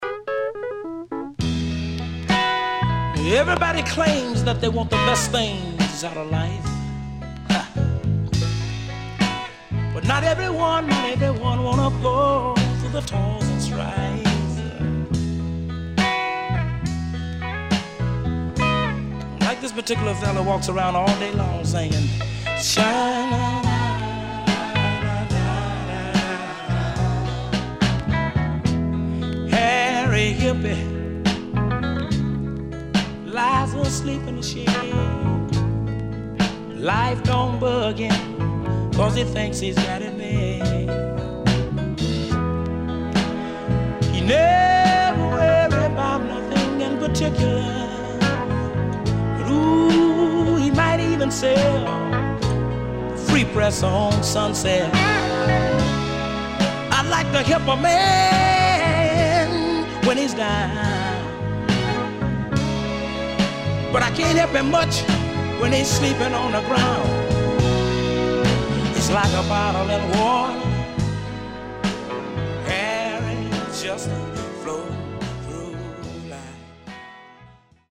CONDITION SIDE A:VG(OK)〜VG+
SIDE A:少しチリノイズ、プチノイズ入ります。